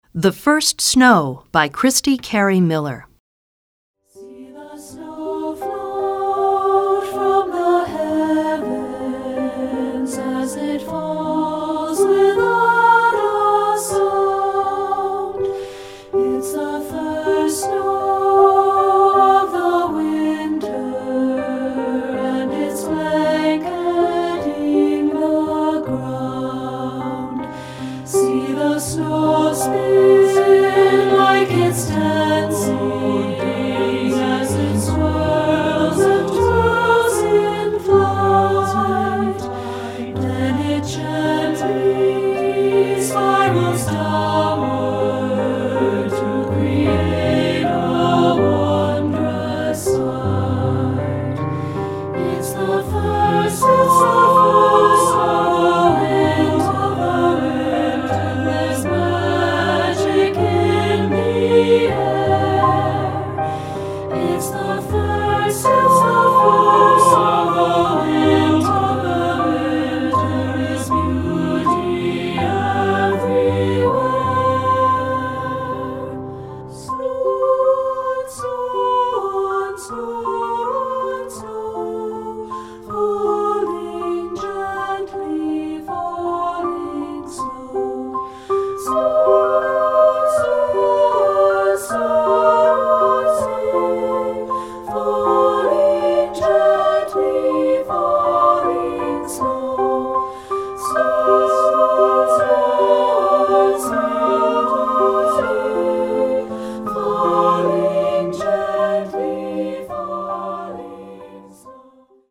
Voicing: 2-Part and Piano